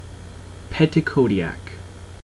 EN-petitcodiac.ogg.mp3